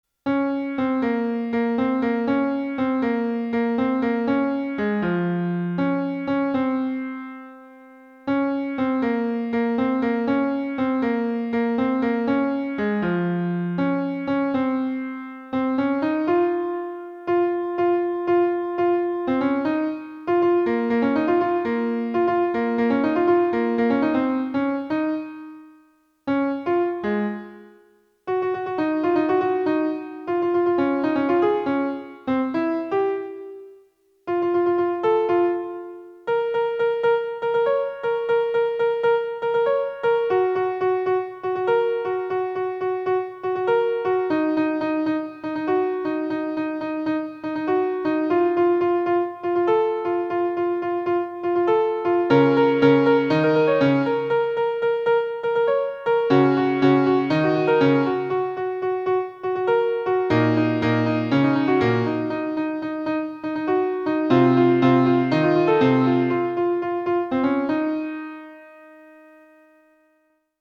Сам наигрыш, снова - с упрощением, в осн. для одной руки:
2.1) просто на пианино